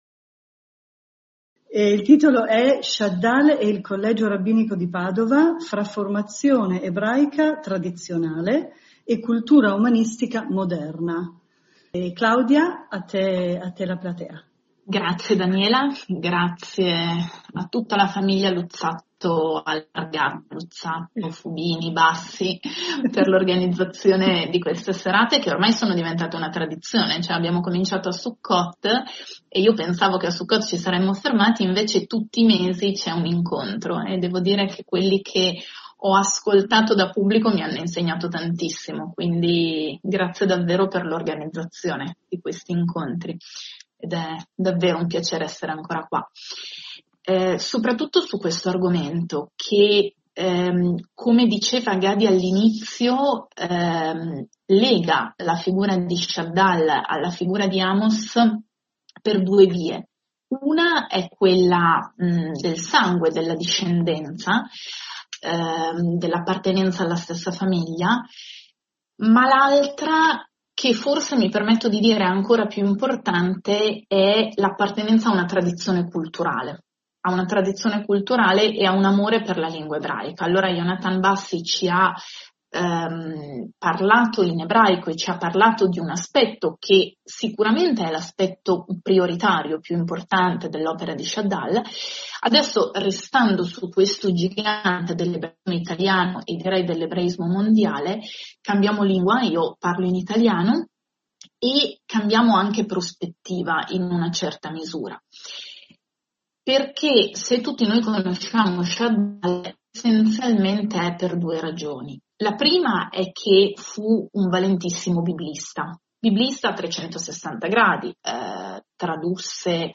Shadal ed il Collegio Rabbinico di Padova tra formazione ebraica tradizionale e cultura umanistica moderna lezione audio